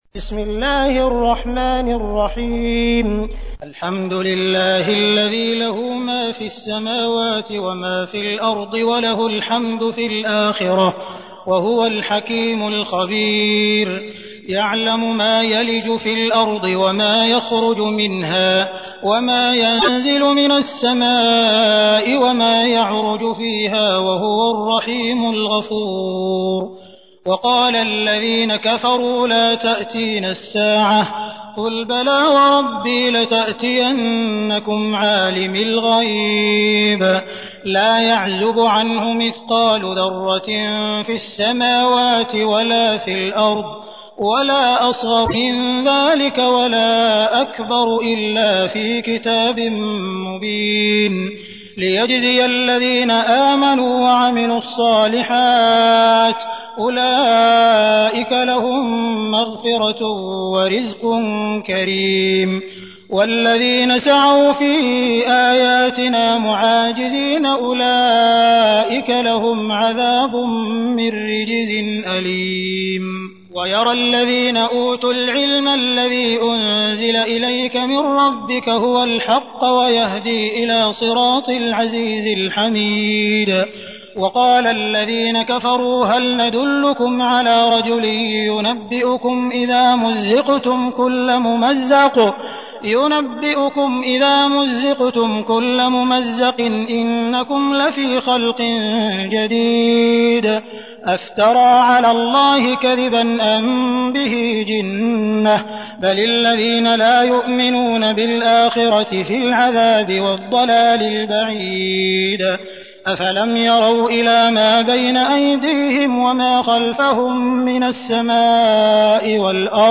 تحميل : 34. سورة سبأ / القارئ عبد الرحمن السديس / القرآن الكريم / موقع يا حسين